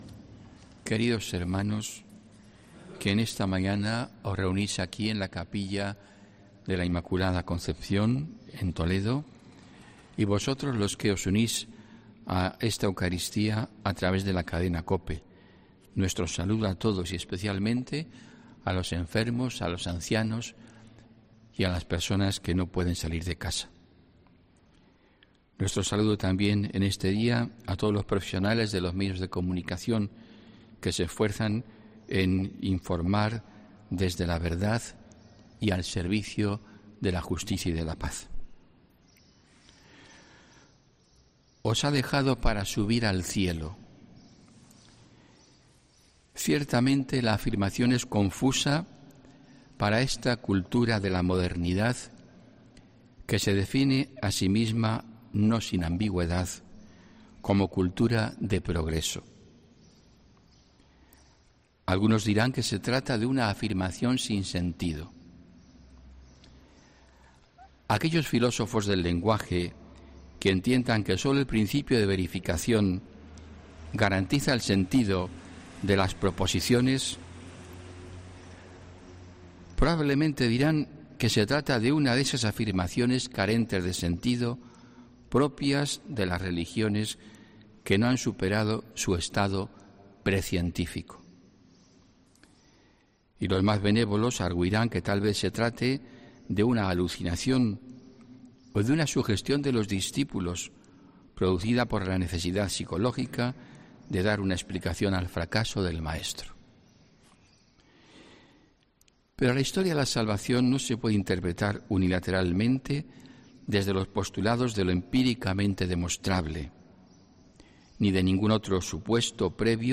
HOMILÍA 16 MAYO 2021